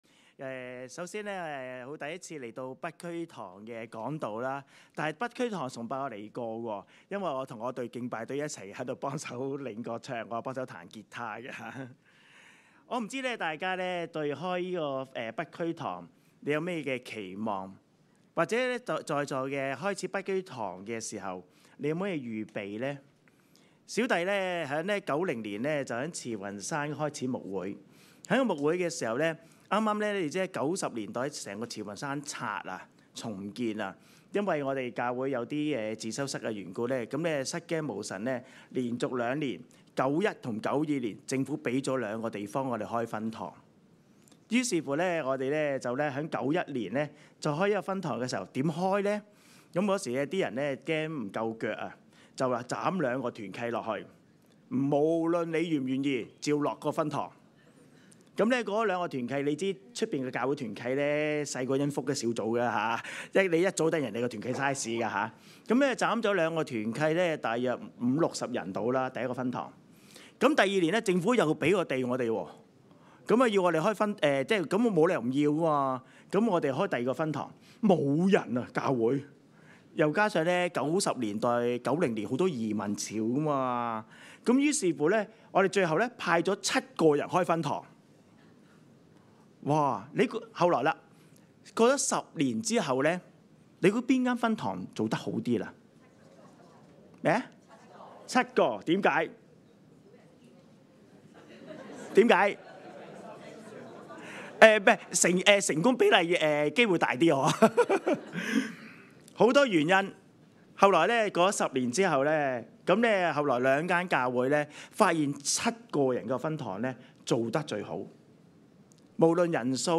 證道集